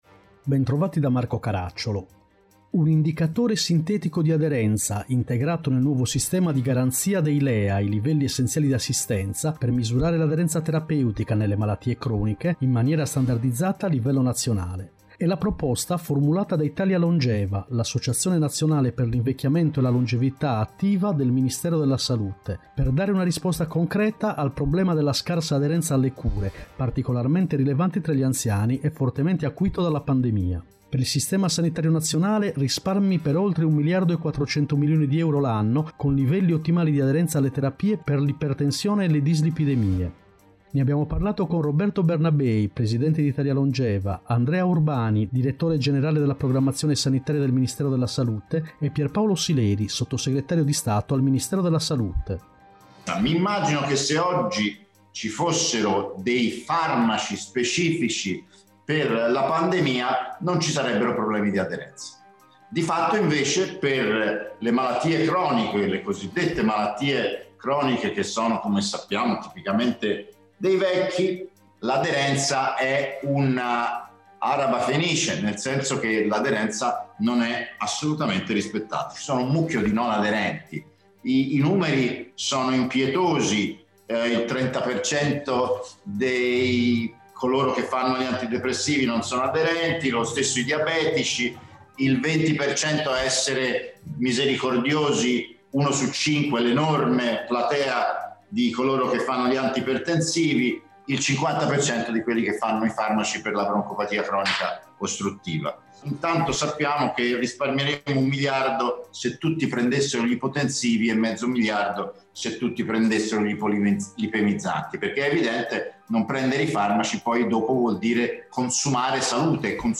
Pierpaolo Sileri, Sottosegretario di Stato al Ministero della salute
Puntata con sigla